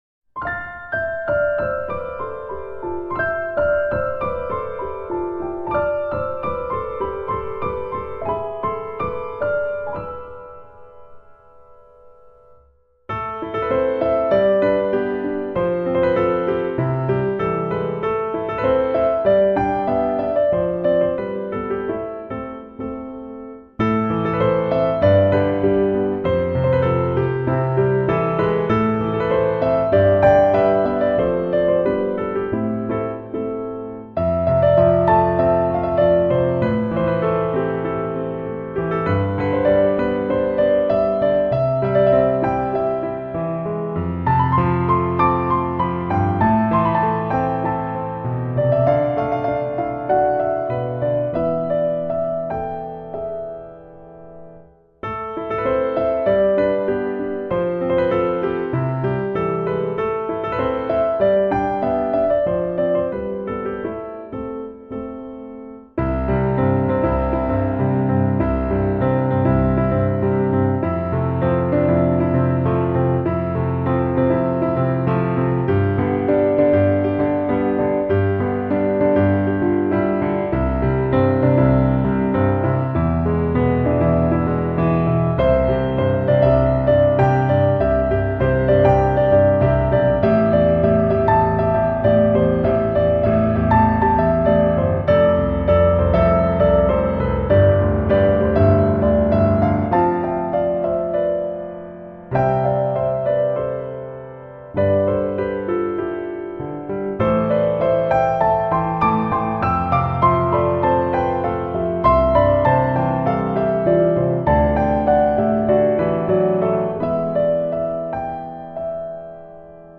ピアノ曲集。